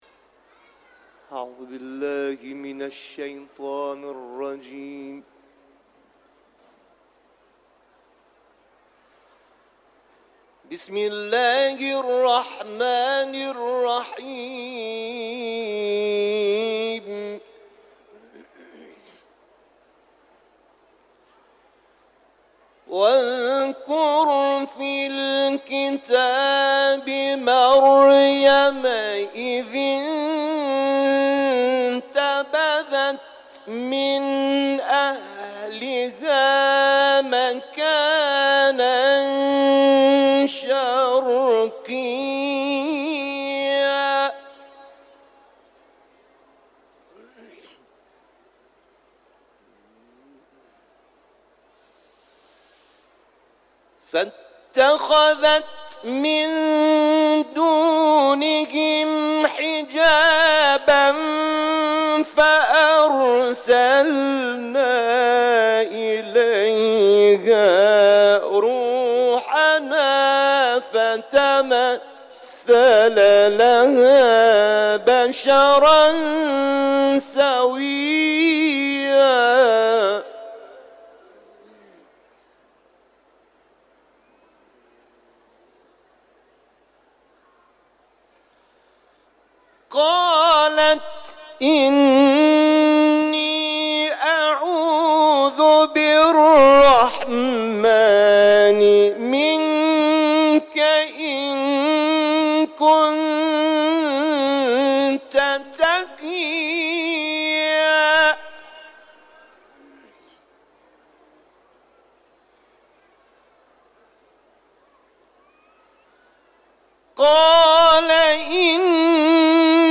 در حرم مطهر رضوی
تلاوت